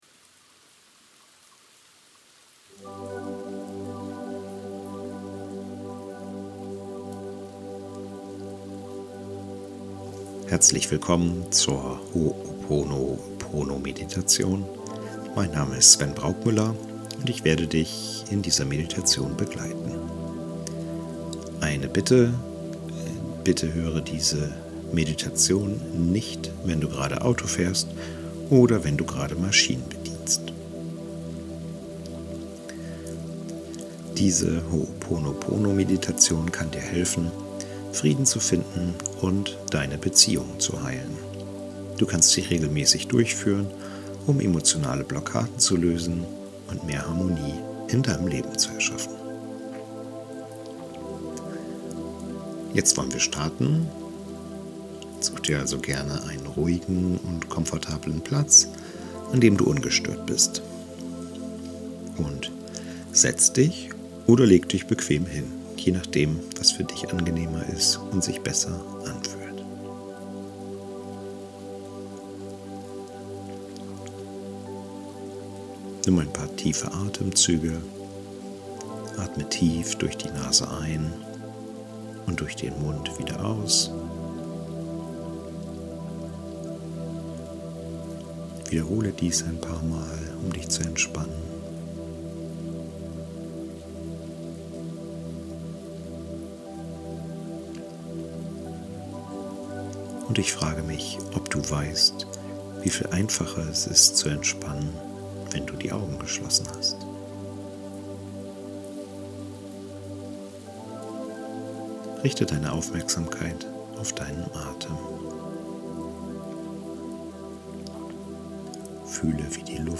Hooponopono_Meditation
Hooponopono_Meditation.mp3